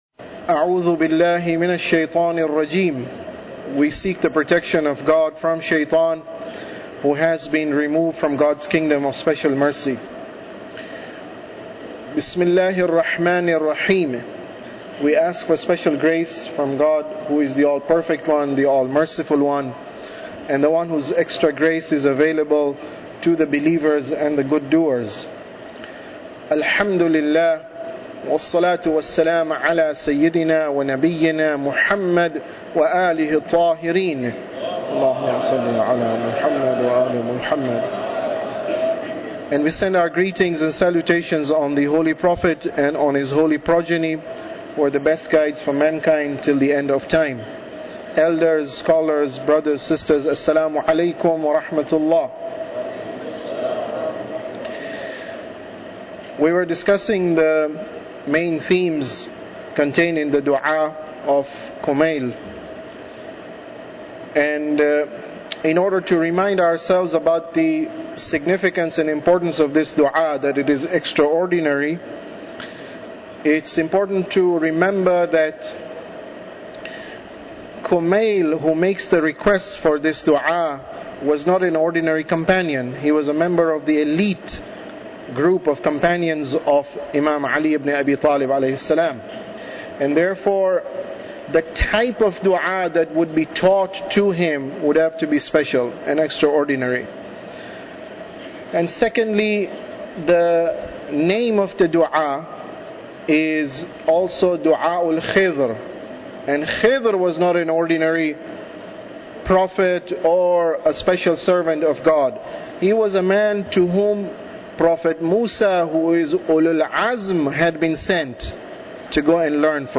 Tafsir Dua Kumail Lecture 19